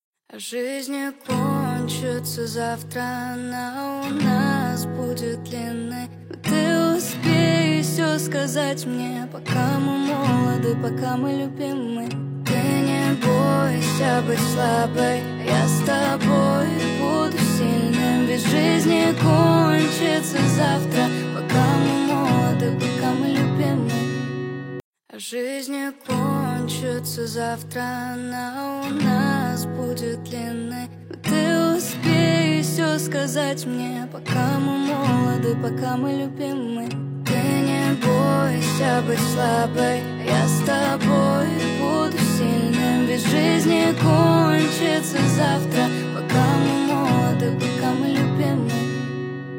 женская версия
девушка поёт